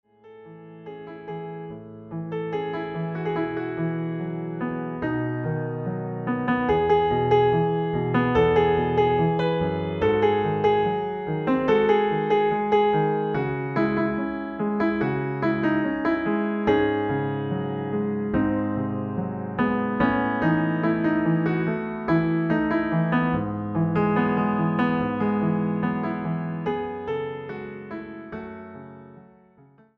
Piano
Piano (acompañamiento)
• Tono original: E